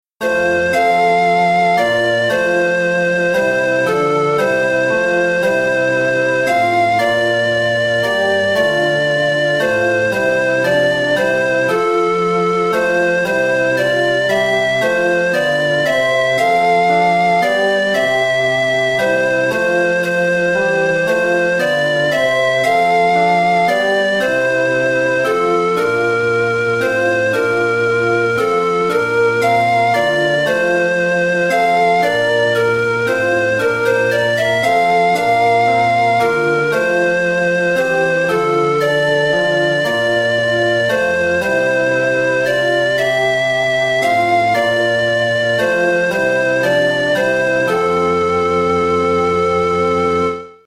Chorproben MIDI-Files 469 midi files